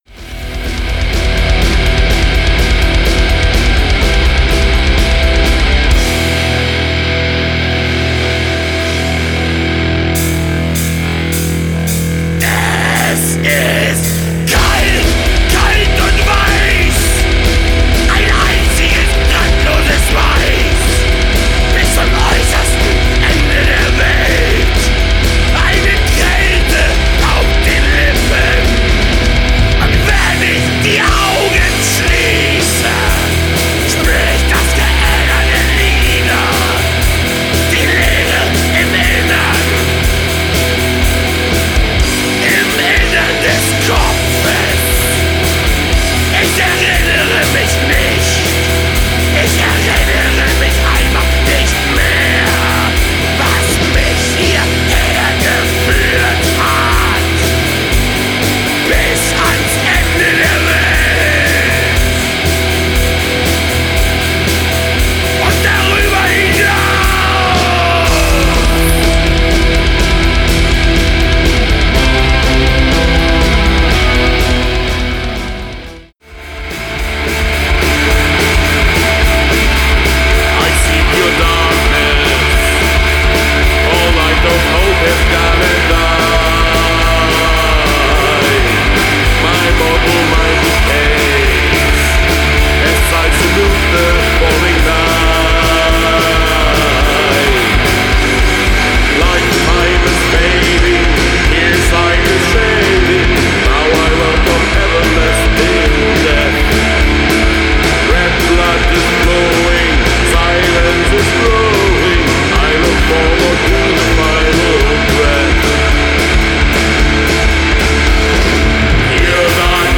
Black & Pagan Metal
es hat auch einen ziemlich rockigen Einfluss.